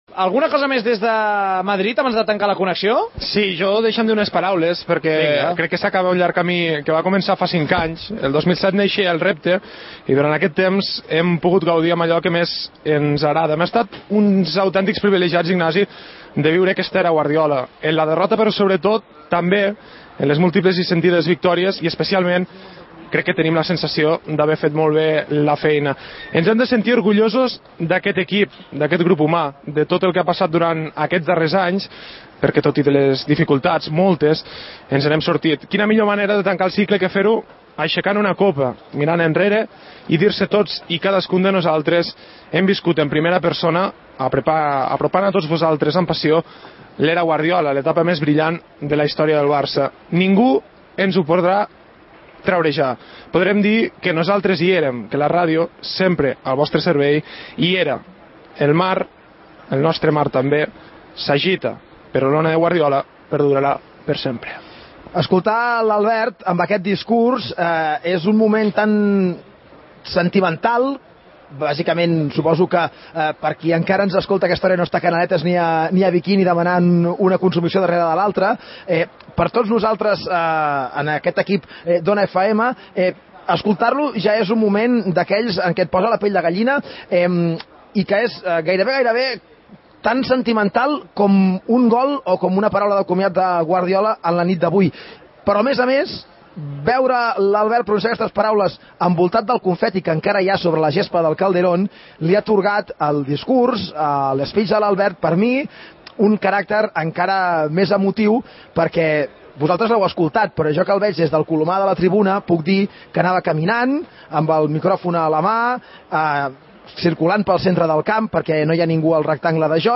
Agraïments dels integrants de l'equip de la transmissió i comiat del que seria l'última transmissió que van fer a Ona FM. Gènere radiofònic Esportiu